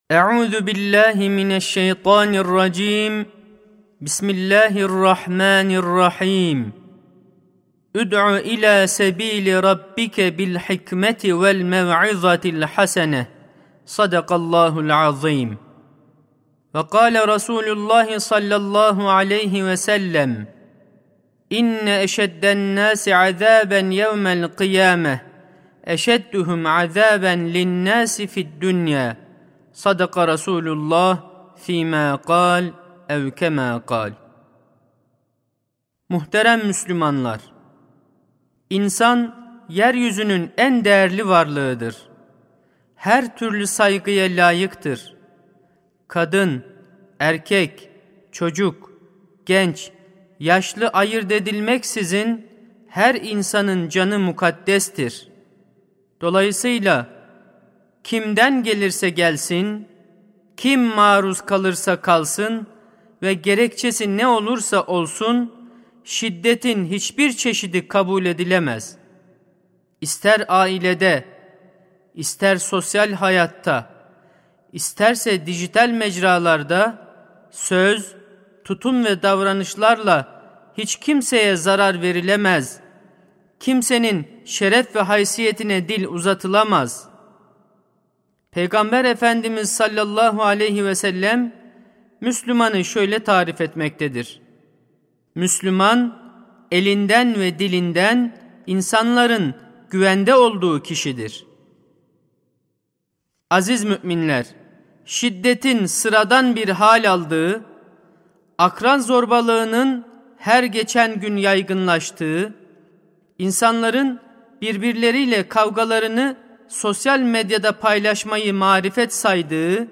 Sesli Hutbe (Şiddetin Çaresi Merhamet Eğitimi).mp3